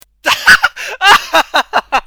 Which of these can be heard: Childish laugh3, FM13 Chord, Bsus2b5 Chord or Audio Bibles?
Childish laugh3